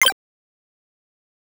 Chiptune Sample Pack
8bit_FX_C_02_01.wav